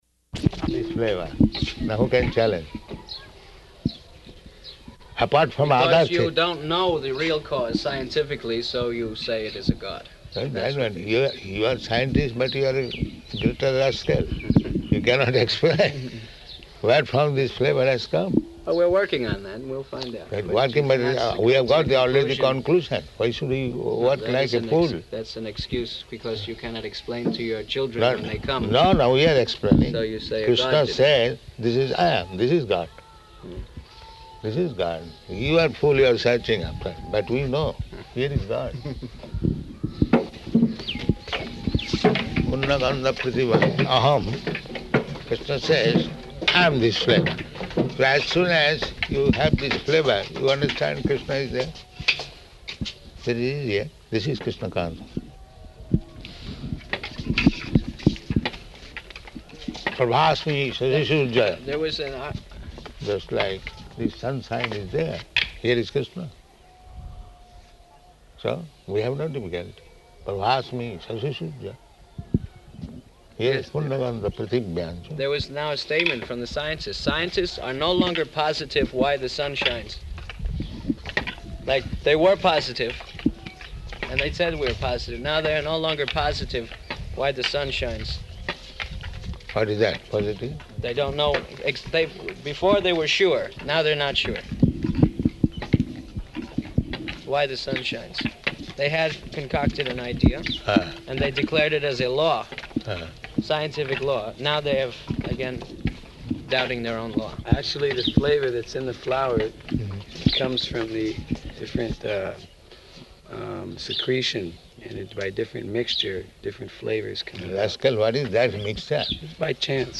Morning Walk --:-- --:-- Type: Walk Dated: February 19th 1976 Location: Māyāpur Audio file: 760219MW.MAY.mp3 Prabhupāda: ...this flavor.